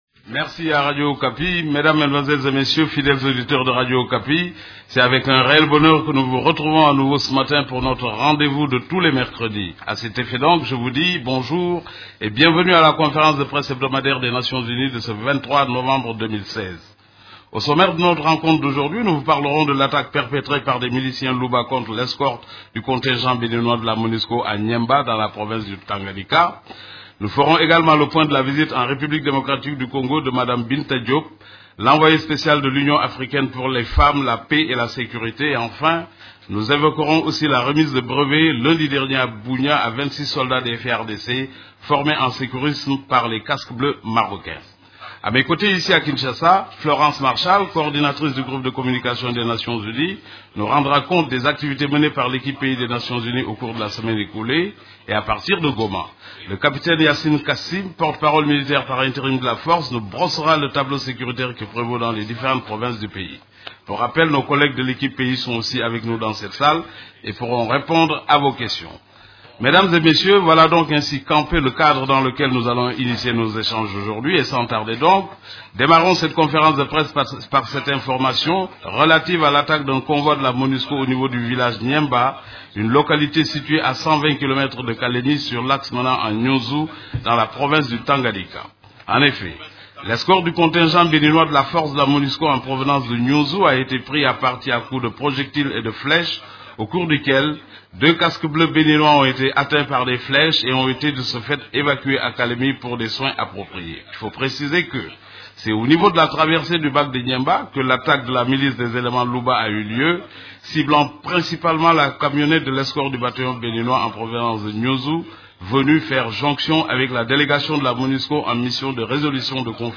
Conférence de presse du 23 novembre 2016
La conférence de presse hebdomadaire des Nations unies du mercredi 23 novembre à Kinshasa a porté sur la situation sur les activités des composantes de la MONUSCO, des activités de l’Equipe-pays ainsi que de la situation militaire à travers la RDC.
Vous pouvez écouter la première partie de la conférence de presse: